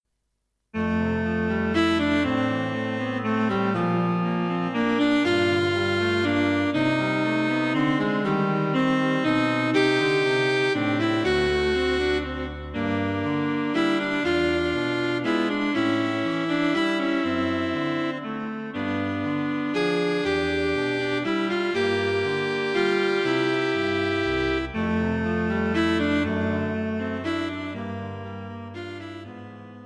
A cheerful set of three pieces for three cellos.
Allegro - Andante - Vivo Running time 6 mins 50 secs.